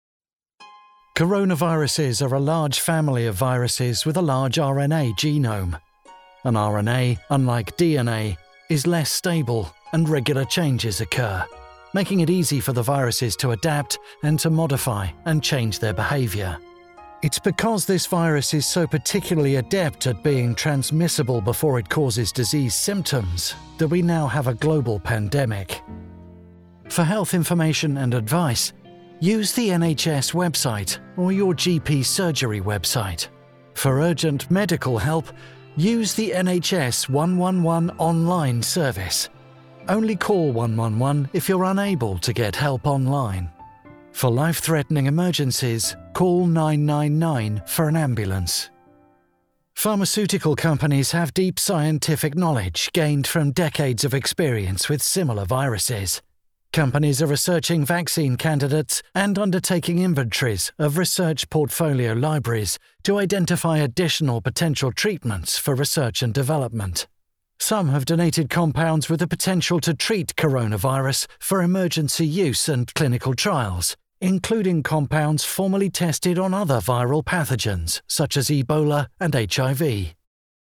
Medical Narration
Experienced, conversational voice actor with warmth and integrity.
Professional Sound Booth